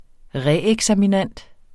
Udtale [ ˈʁε- ]